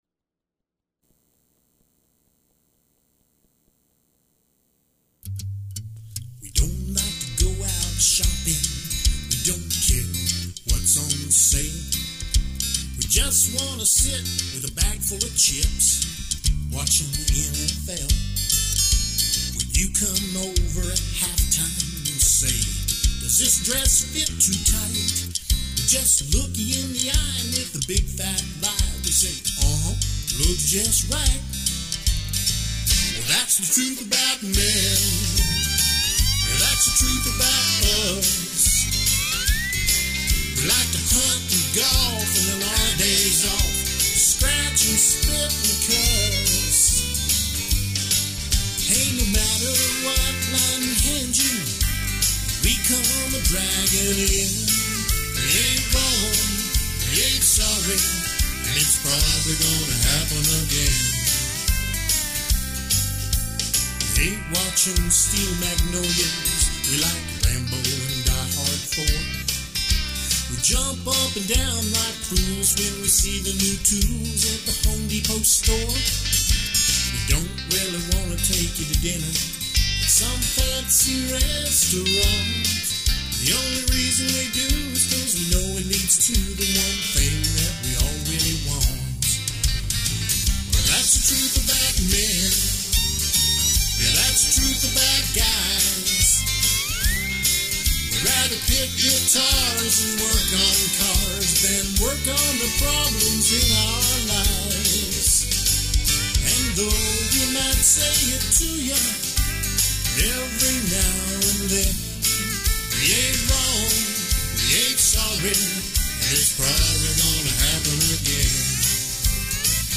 Drum machine